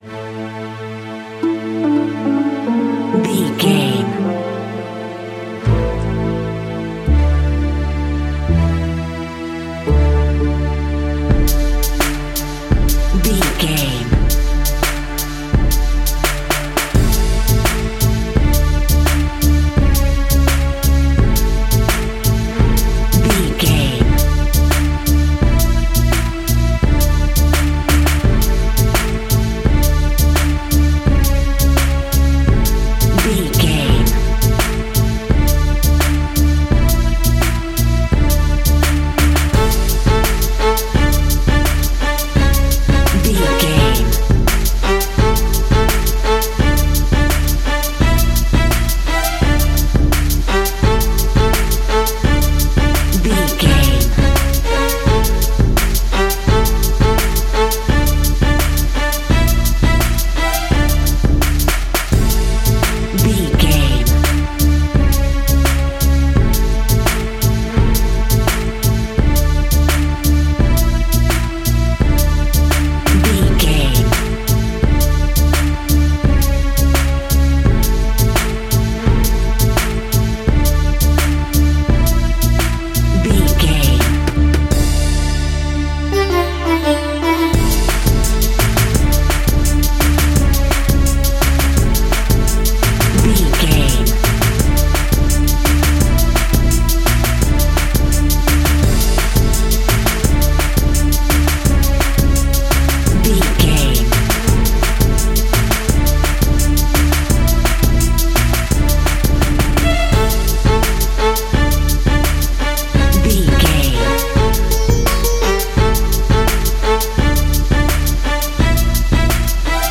Aeolian/Minor
Slow
World Music
percussion